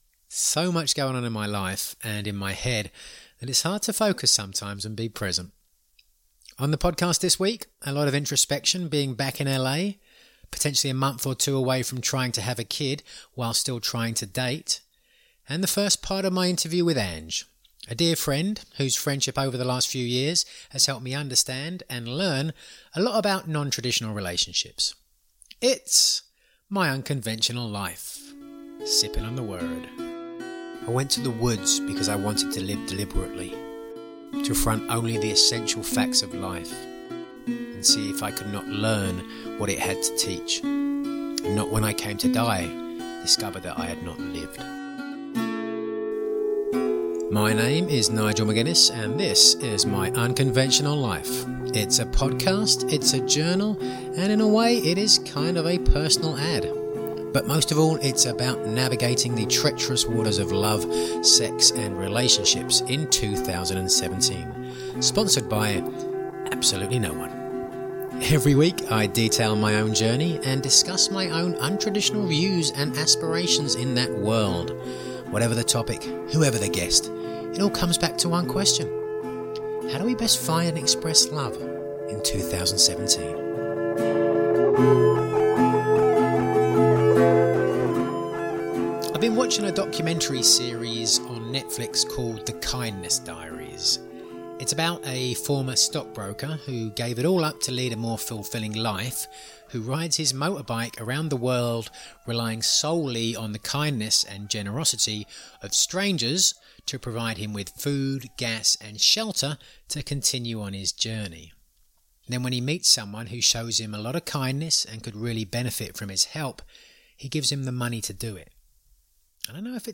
Monologue finally back in LA, detailing the various thoughts and ideas regarding dating and parenting inspired recently by books and TV.